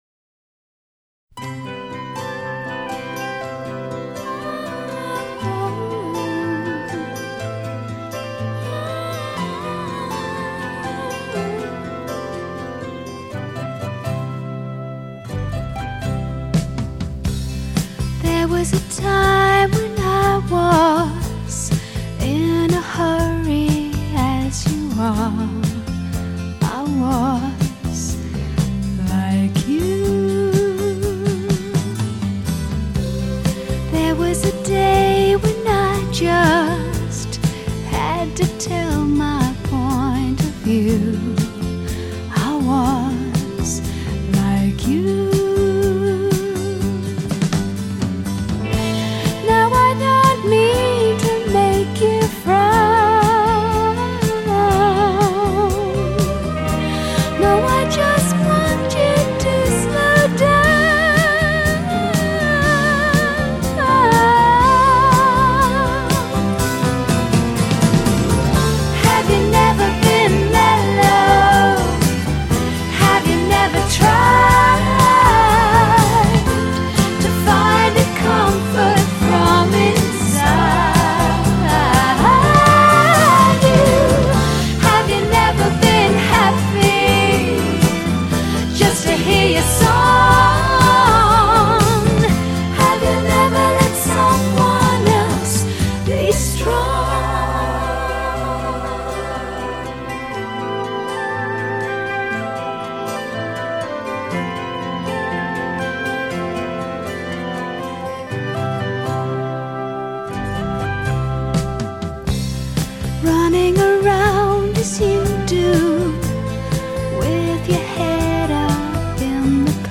австралийской певицы